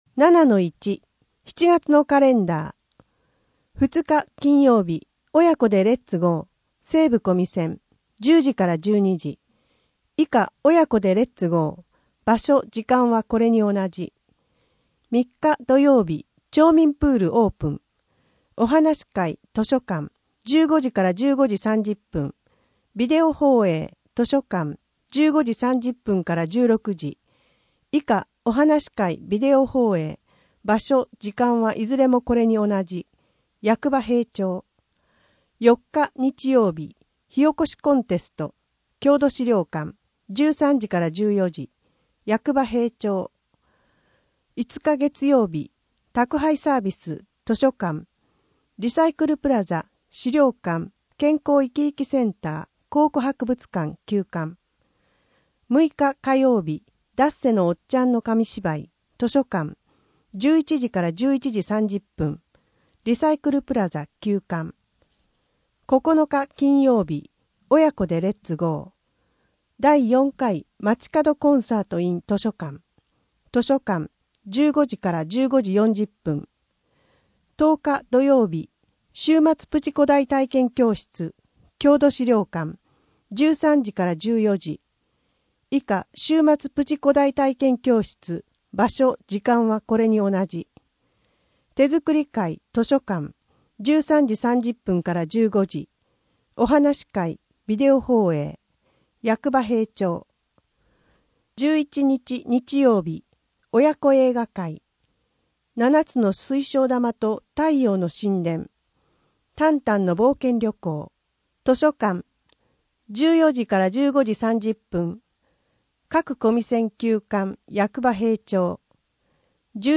声の「広報はりま」7月号
声の「広報はりま」はボランティアグループ「のぎく」のご協力により作成されています。